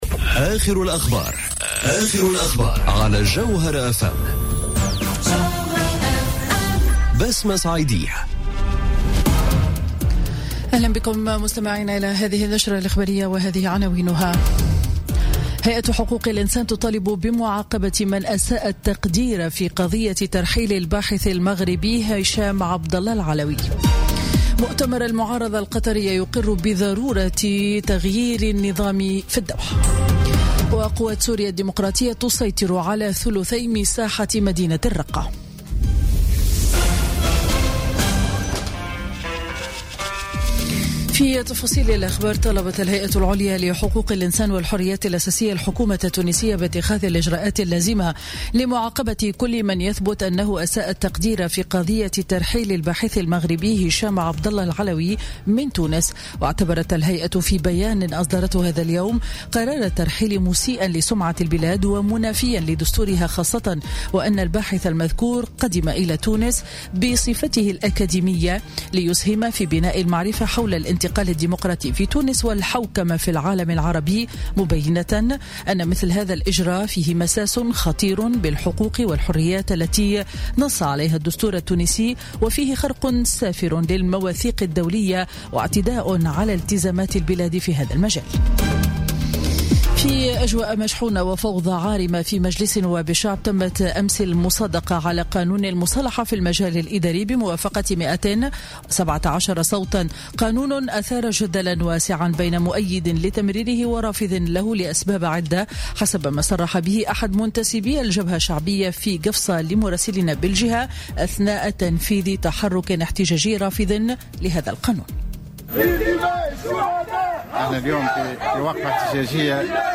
نشرة أخبار منتصف النهار ليوم الخميس 14 سبتمبر 2017